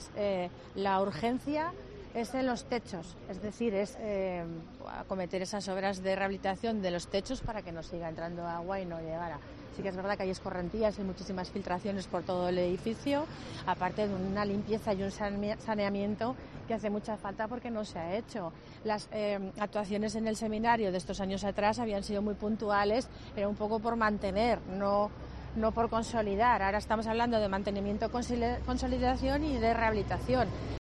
Lorena Orduna es alcaldesa de Huesca